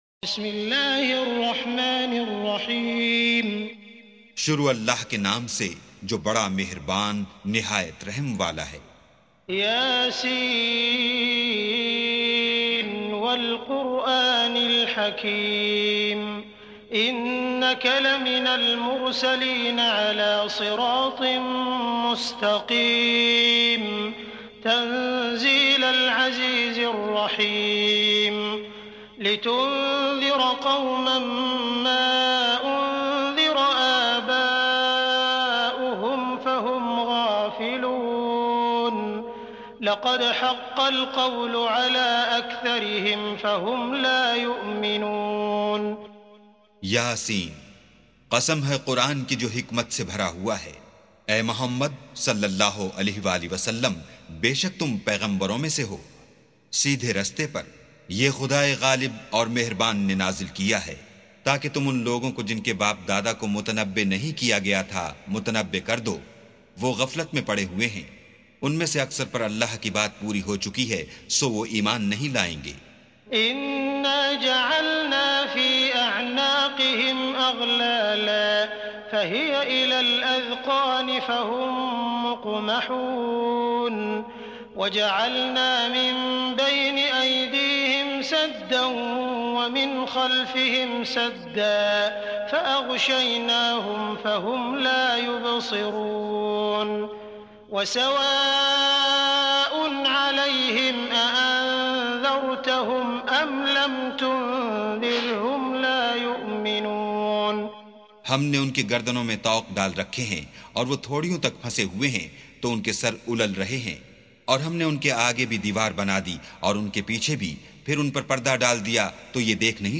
سُورَةُ يسٓ بصوت الشيخ السديس والشريم مترجم إلى الاردو